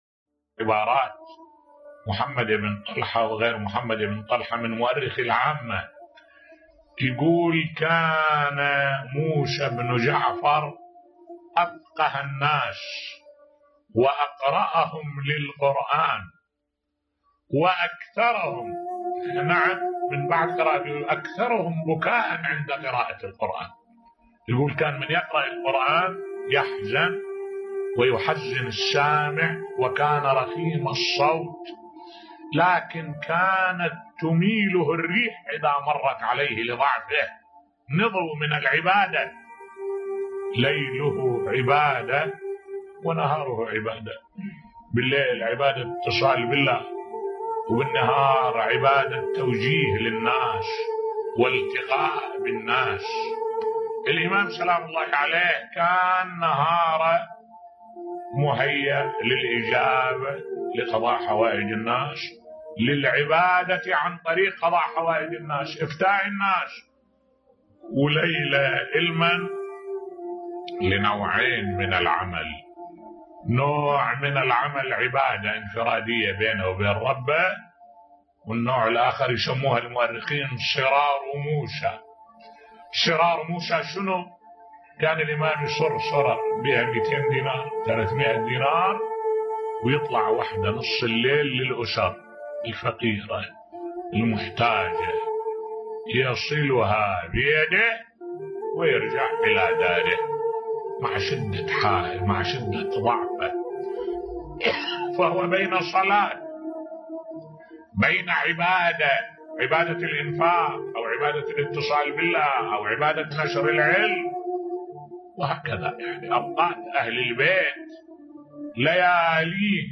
ملف صوتی عبادة الامام الكاظم (ع) بصوت الشيخ الدكتور أحمد الوائلي